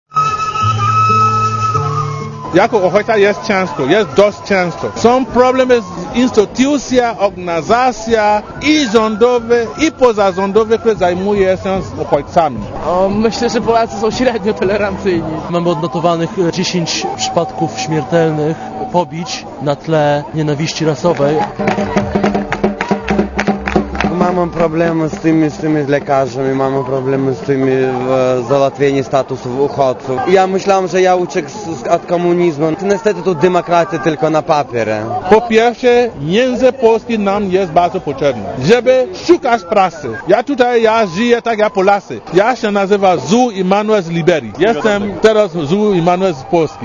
* Posłuchaj, co mówią uchodźcy mieszkający w Polsce*